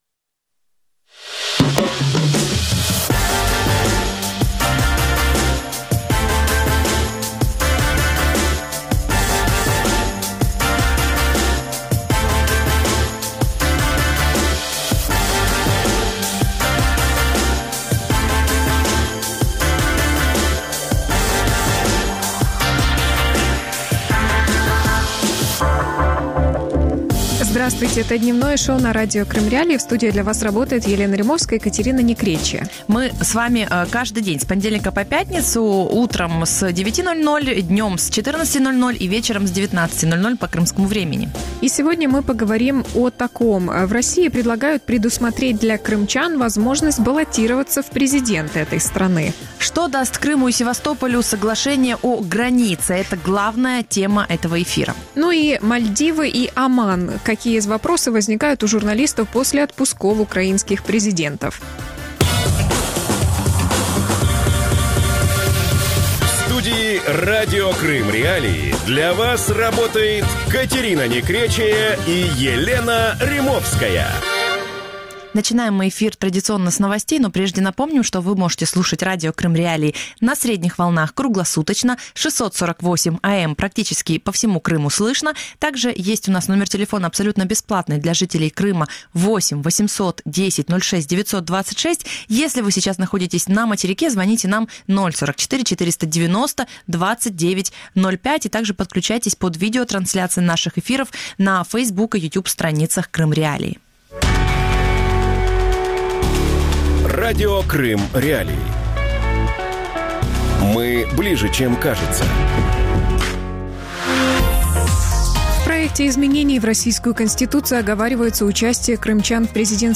Крым и Севастополь делят полуостров | Дневное ток-шоу